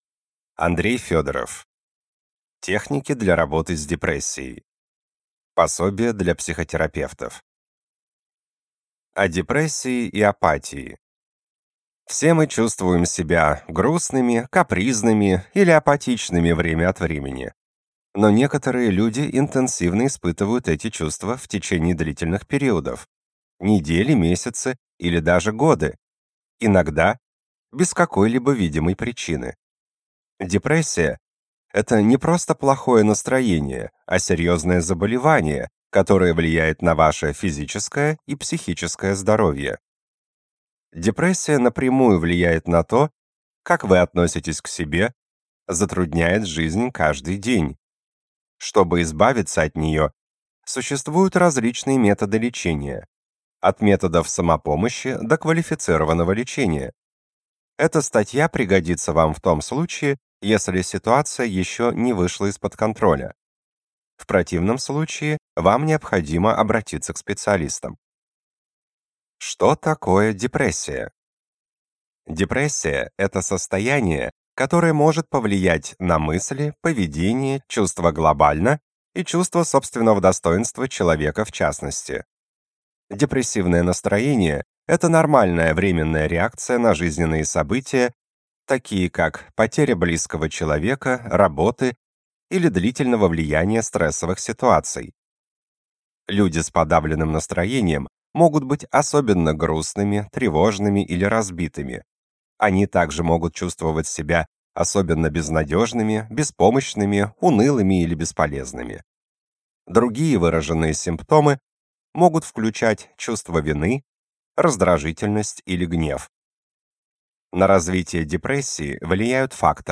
Аудиокнига Техники для работы с депрессией | Библиотека аудиокниг